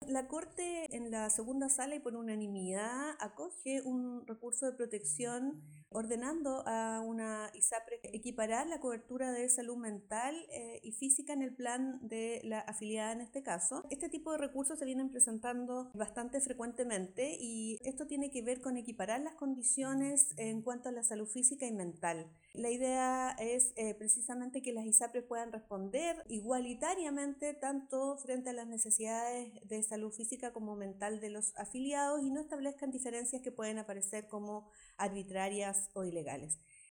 Al respecto, la ministra Gloria Negroni informó que: